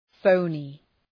{‘fəʋnı}